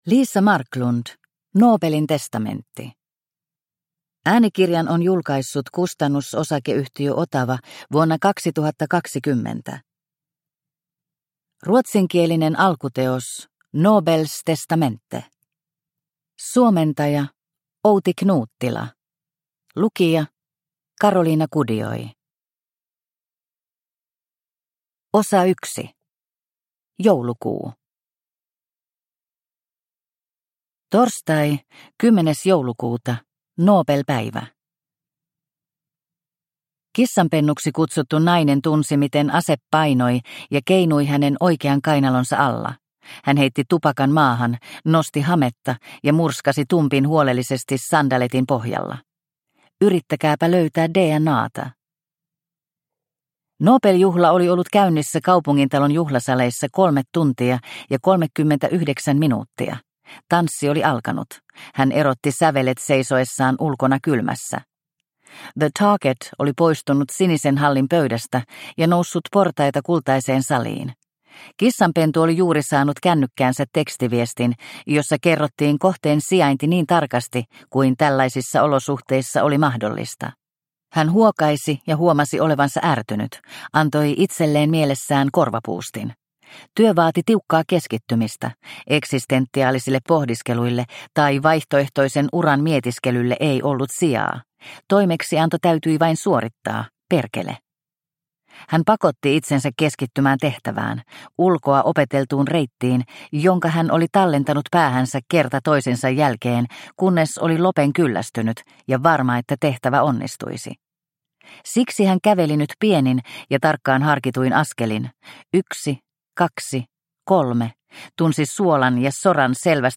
Nobelin testamentti – Ljudbok – Laddas ner